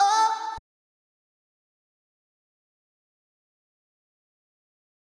SG - Vox 4.wav